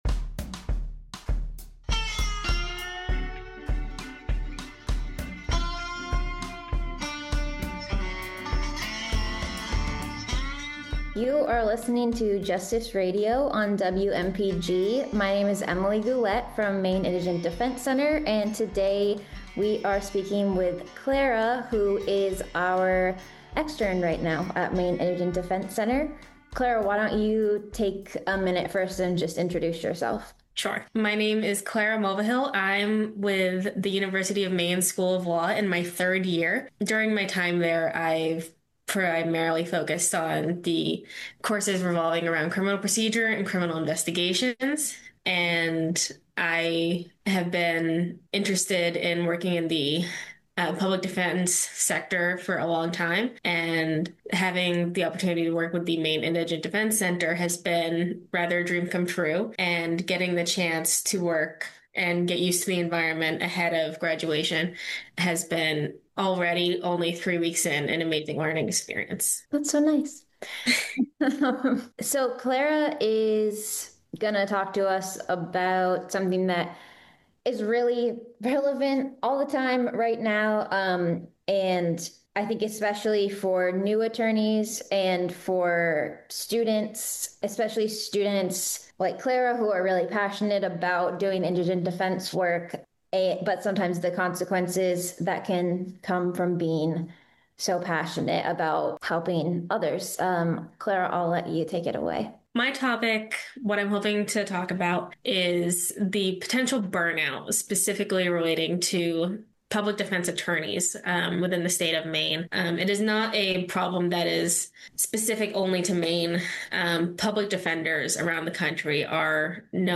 MP3 Audio Archive Recordings (aka Podcasts) of all locally produced spoken word programming from Community Radio WERU 89.9 FM Blue Hill, Maine - Part 37